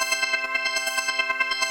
SaS_MovingPad04_140-C.wav